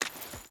Water Chain Run 3.ogg